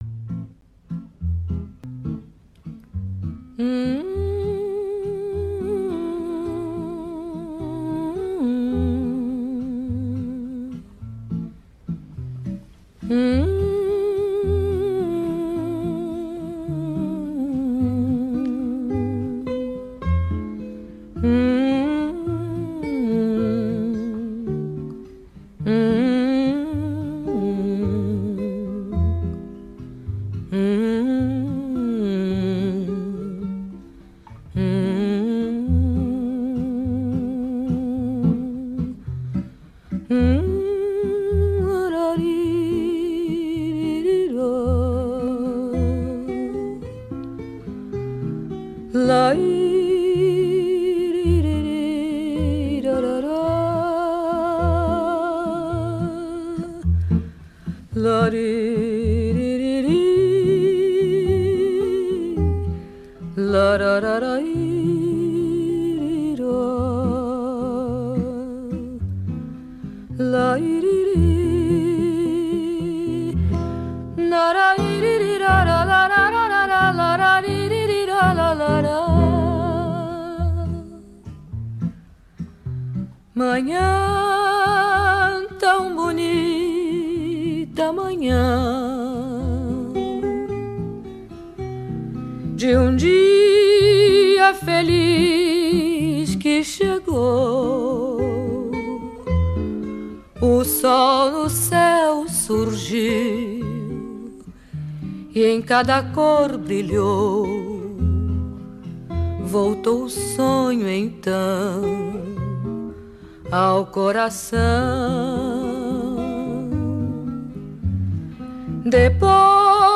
Listen and Enjoy the wonderful Brasilian vocalist
A haunting, tender song